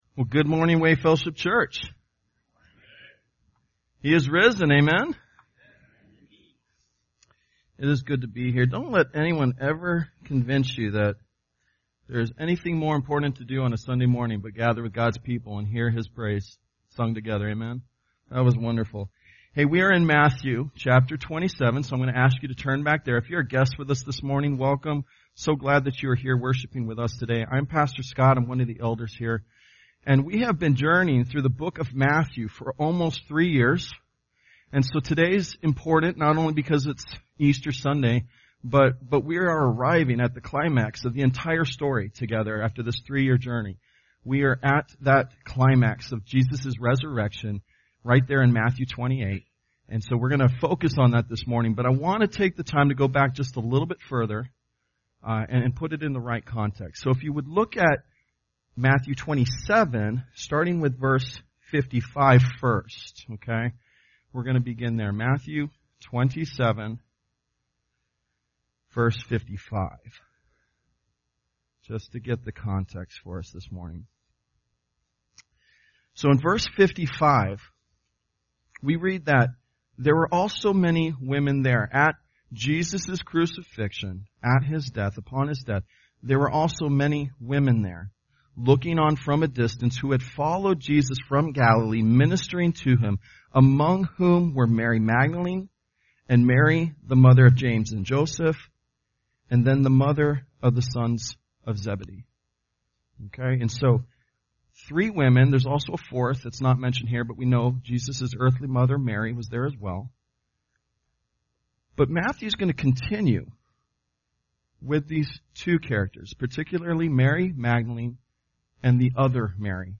Sunday Worship
Tagged with Easter , Sunday Sermons